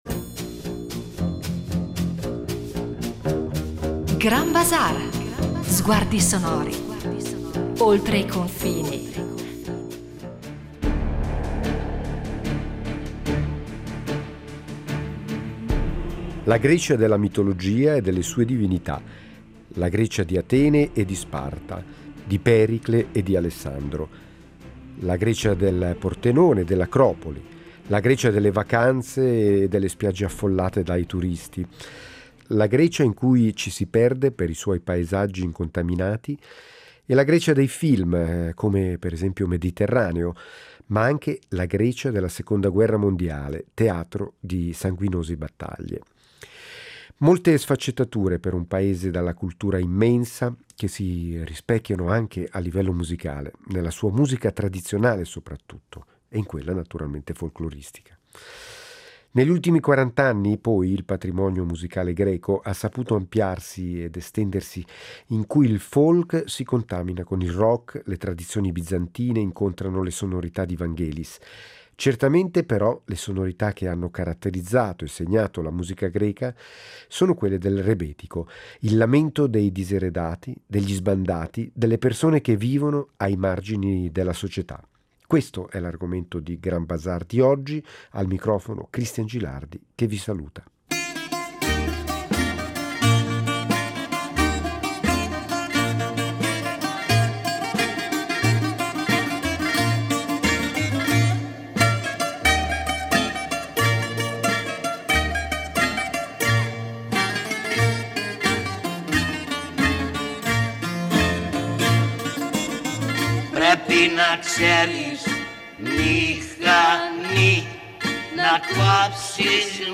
La puntata di “Grand Bazaar” è interamente dedicata al rebetico, musica dei diseredati e delle minoranze urbane, in cui amori, droga, repressione da parte delle autorità sono i temi di quello che potremmo definire il blues europeo. Dalle prime esperienze nelle tékes, i locali fumosi nel quartiere del Pireo, fino alle nuove esperienze musicali che caratterizzano un universo musicale affascinante, in fondo ancora poco noto… tutto questo in “Grand Bazaar”.